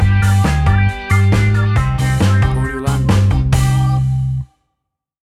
Classic reggae music with that skank bounce reggae feeling.
Tempo (BPM): 136